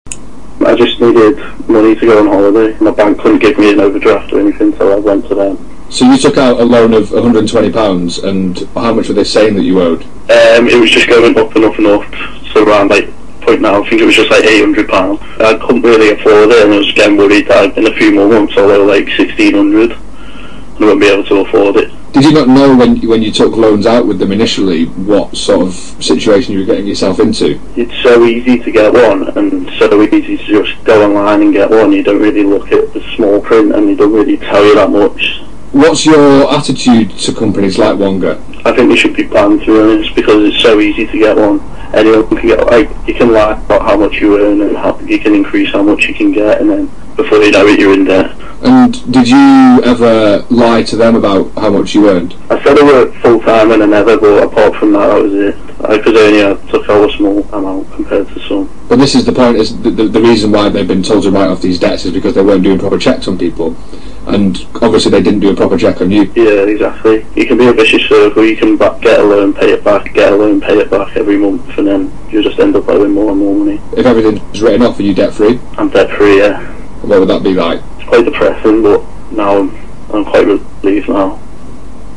In an interview with the BBC, former Wonga customer